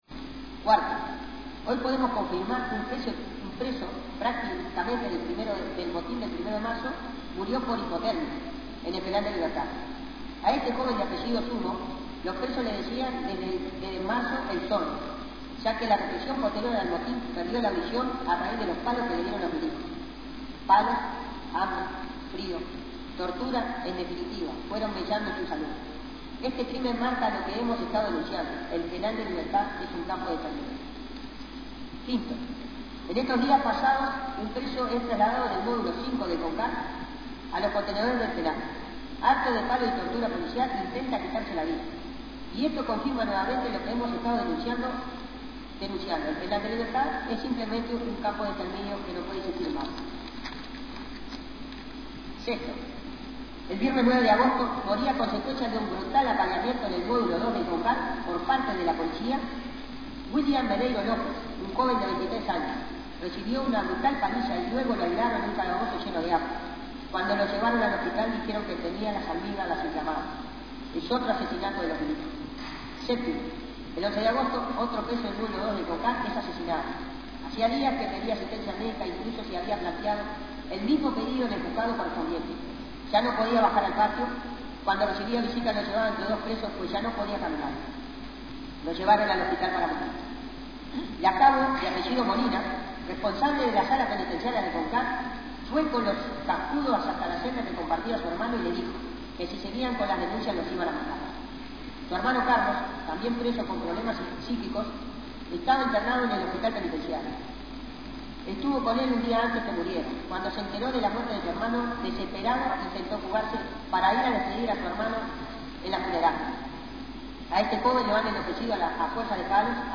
Comenzó leyendo un comunicado emitido por Familiares de Presos en Lucha y el Comité por la Libertad de los Muchachos Presos. Concluyó desarrollando ampliamente la situación que viven los presos sociales en los campos de concentración, especialmente en las ruinas del Penal de «Libertad».